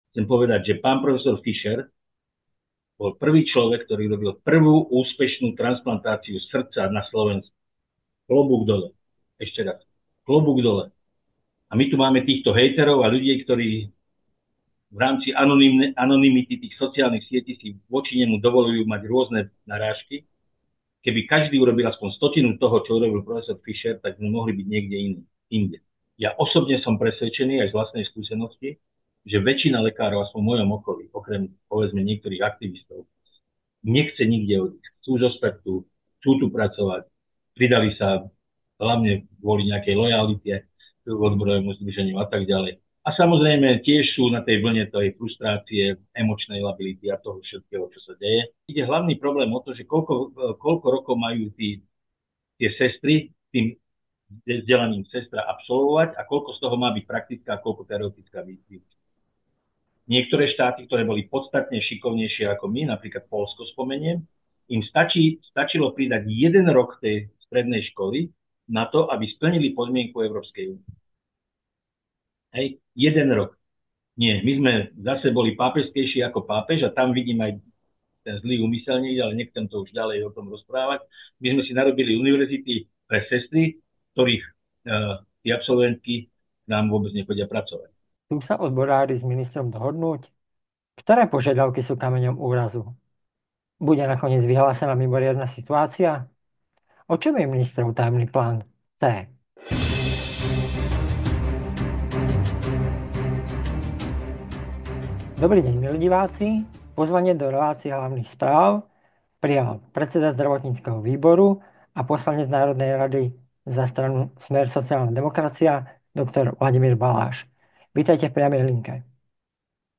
Aj v našom rozhovore vyjadril podozrenie, že mu to začína pripomínať scenár, ktorý má viesť k pádu súčasnej vlády.